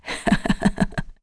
Seria-Vox_Happy1.wav